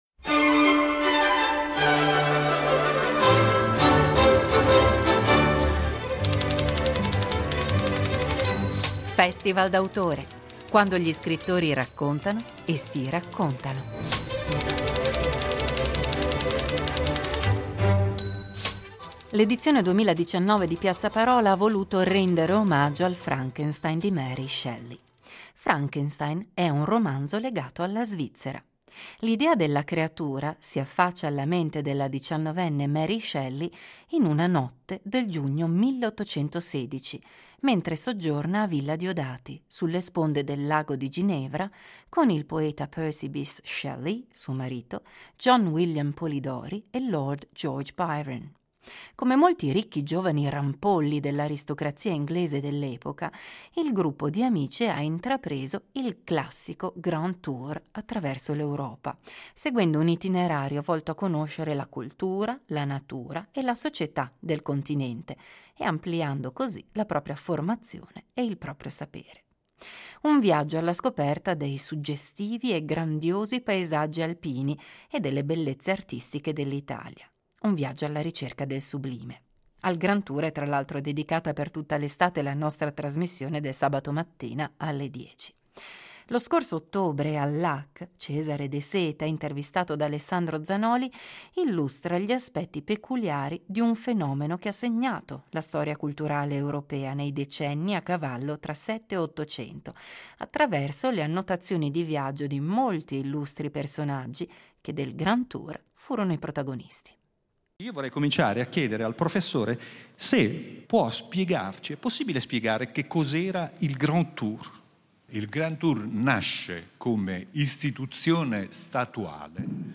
Lo scorso ottobre al LAC